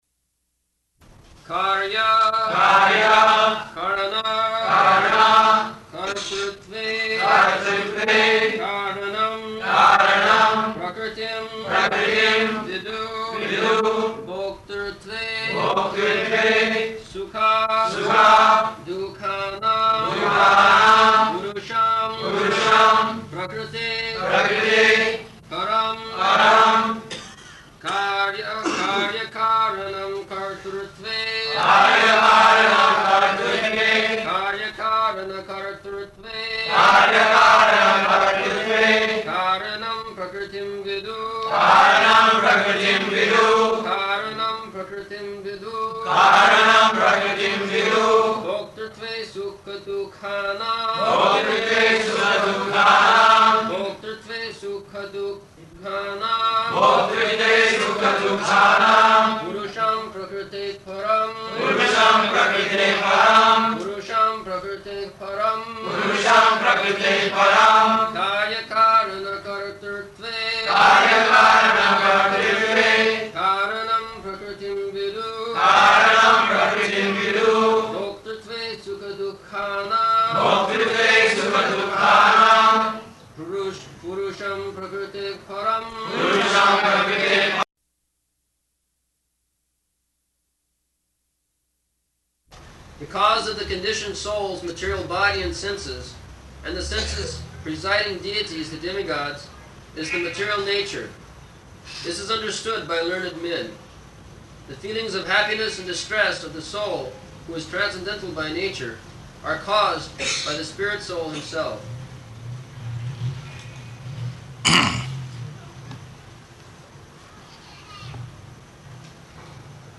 -- Type: Srimad-Bhagavatam Dated: December 20th 1974 Location: Bombay Audio file